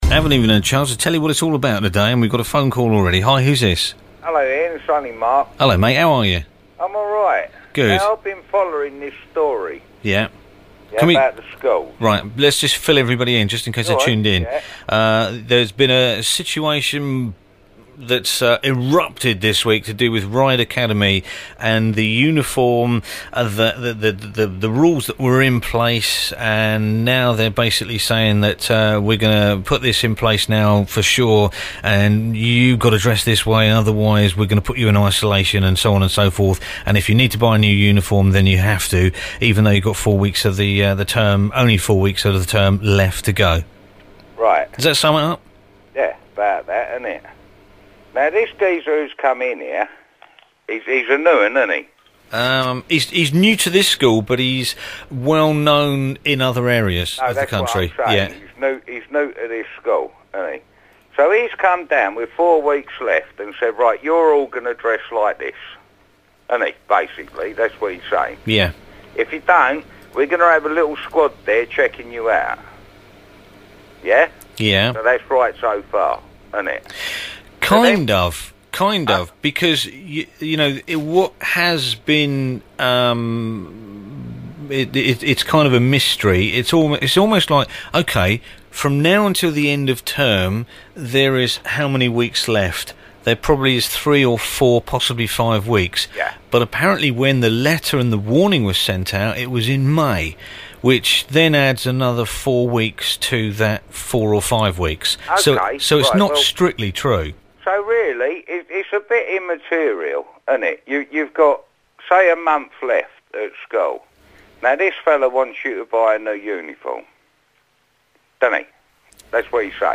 This Weeks Thursday Phone In